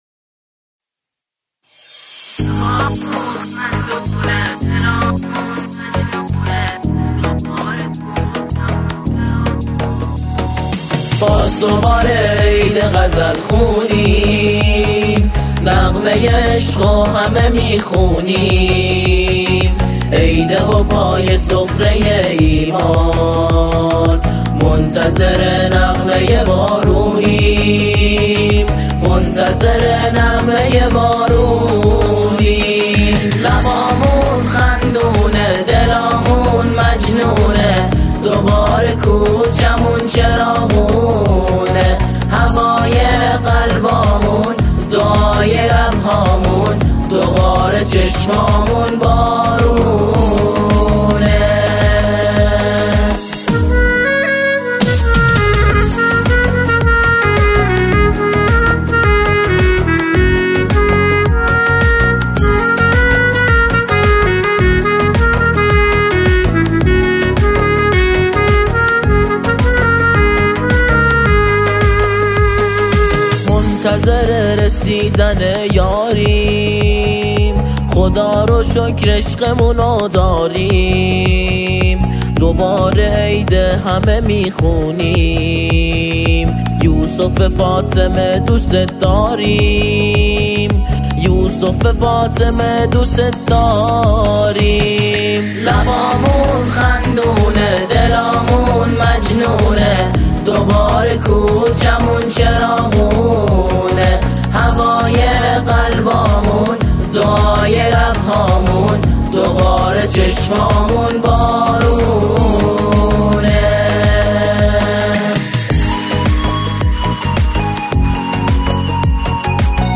سرودهای امام زمان (عج)
اجرای گروه همخوان در تکرار ترجیع